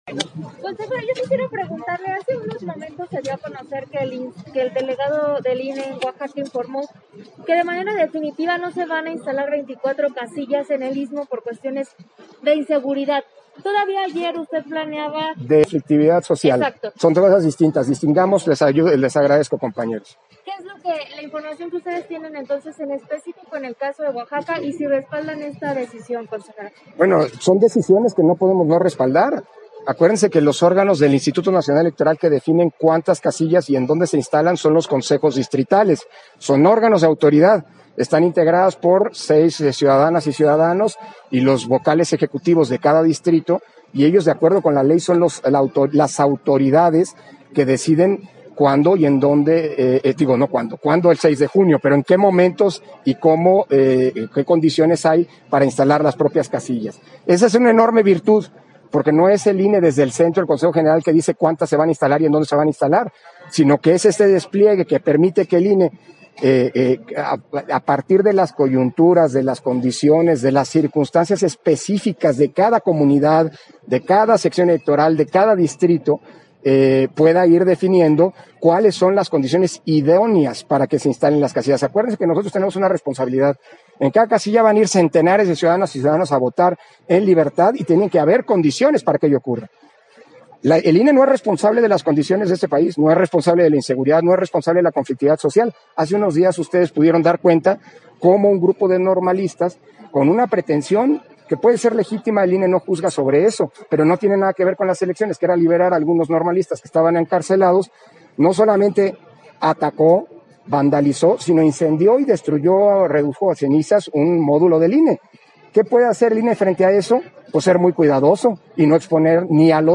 040621_AUDIO_ENTREVISTA-CONSEJERO-PDTE.-CÓRDOVA-ENTREGA-DEL-INFORME-FINAL-DE-AUDITORÍA-AL-PREP - Central Electoral